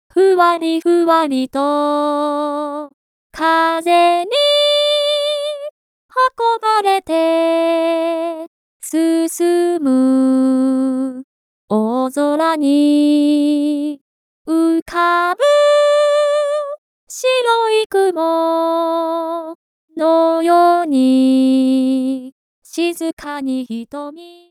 上の段落での音符分割はギターのスライド奏法のような音程の変化でしたが、ここでは同じギターのチョーキング奏法という技術と同じような無段階の滑らかな音程変化を指定します。
最後のピッチベンドを使ってしゃくり上げを作ったものは、次でお話しするビブラートの表現も処理済です。
ピッチベンドでのしゃくり上げ